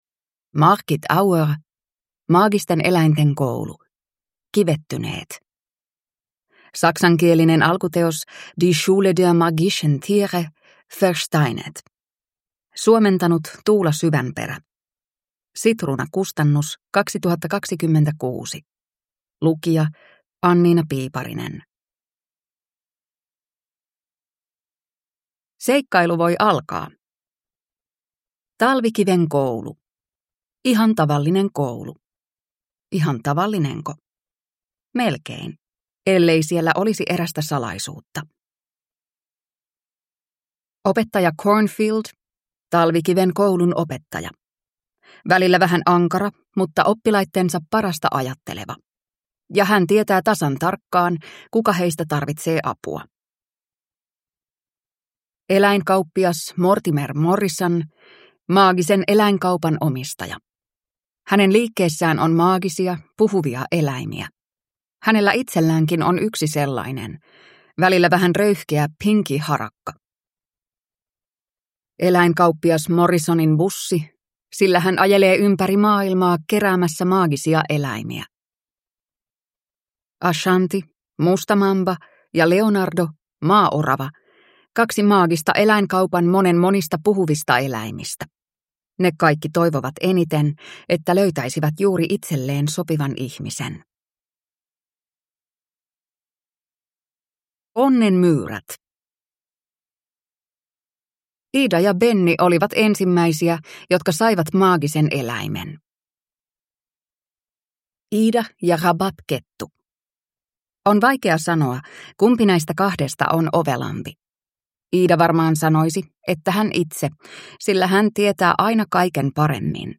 Maagisten eläinten koulu 9 - Kivettyneet! – Ljudbok